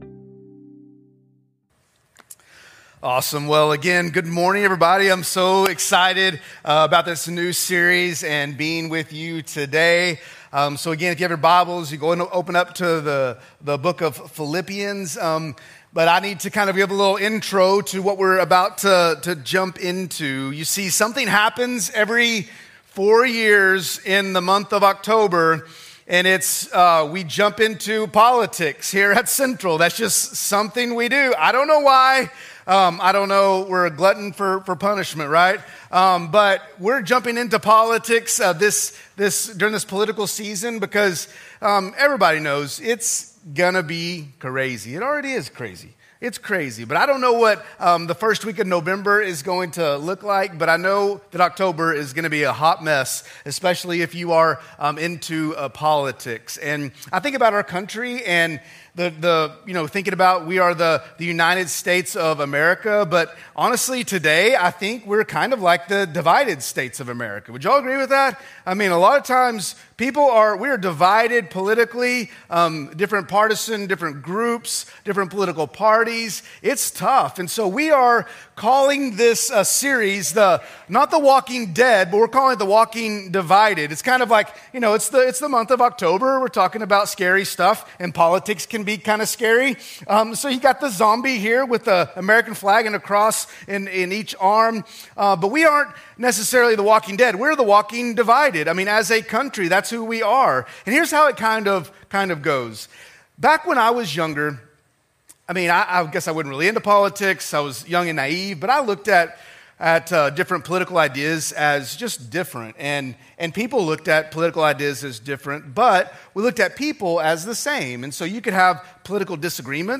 Download Watch Listen Details Share Sermon Notes From Series